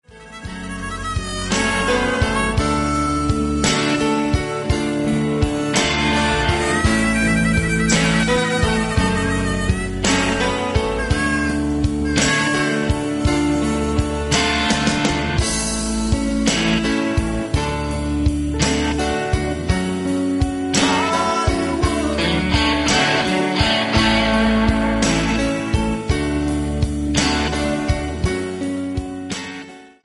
MP3 – Original Key – Backing Vocals Like Original
Pop